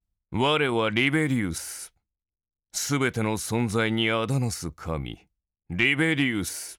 第一则配音片段：
狂野倨傲，蔑视一切的气场，光是念出名字就足以hold住全场，这才是属于大反派李贝留斯的声音啊!